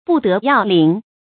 注音：ㄅㄨˋ ㄉㄜˊ ㄧㄠˋ ㄌㄧㄥˇ
不得要領的讀法